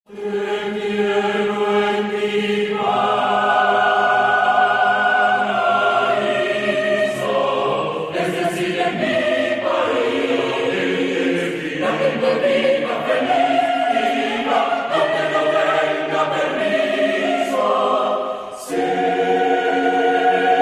9 polyphonies a cappella pour voix mixtes
Certains passages sont réservés aux solistes.